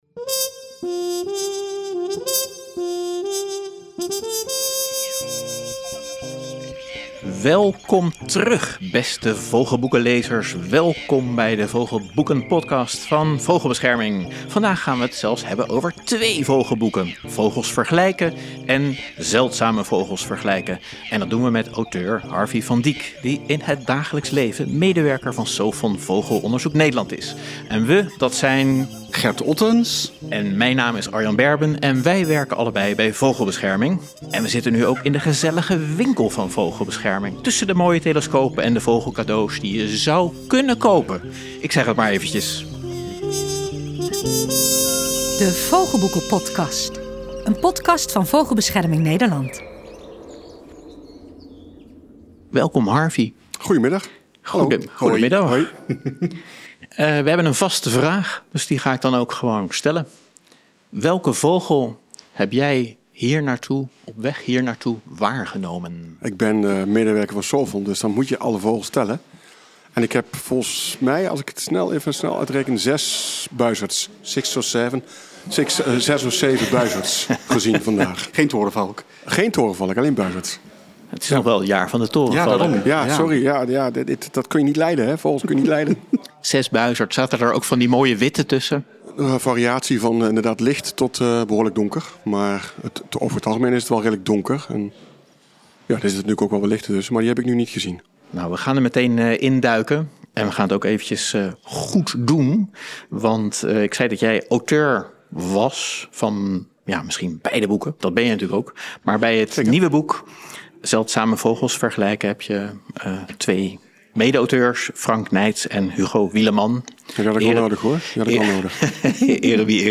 In gesprek
opgenomen in de gezellige Winkel van Vogelbescherming.